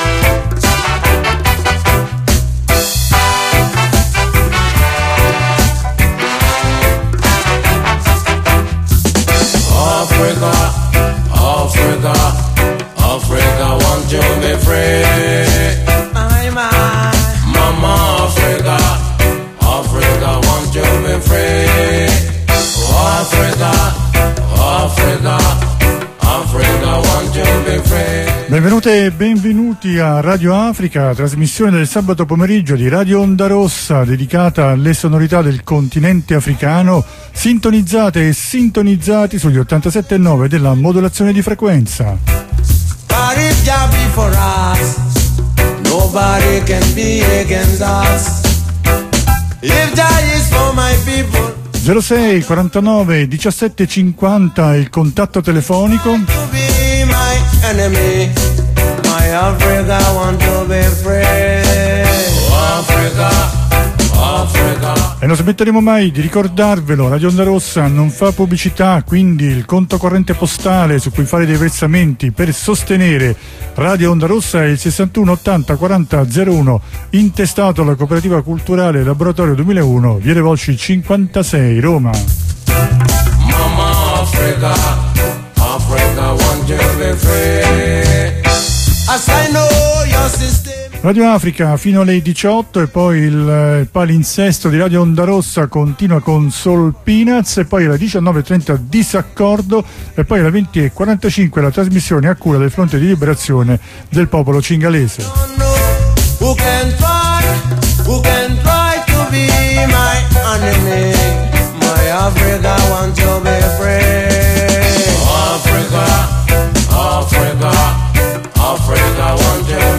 Musica africana | Radio Onda Rossa